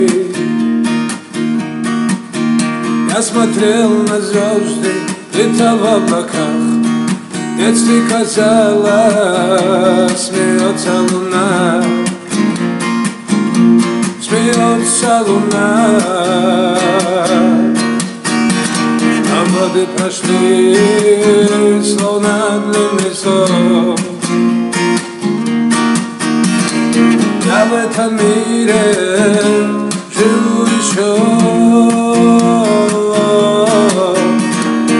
Жанр: Фолк-рок / Русские